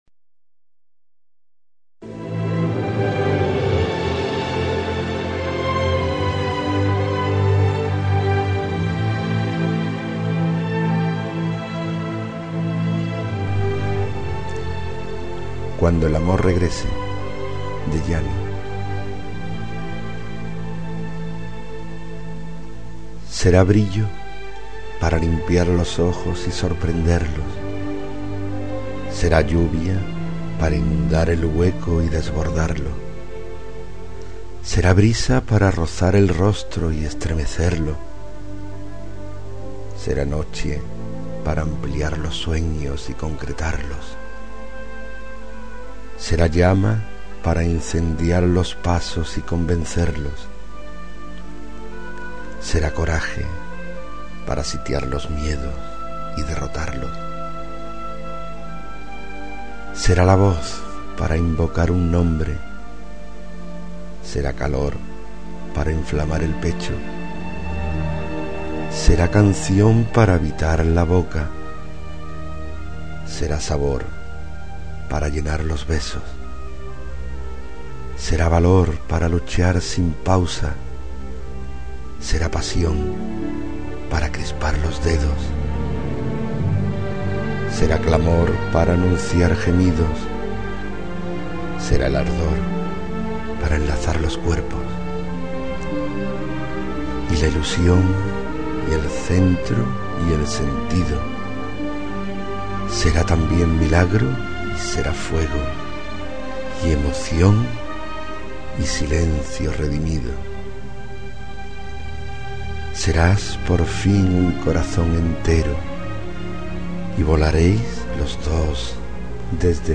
Inicio Multimedia Audiopoemas Cuando el amor regrese.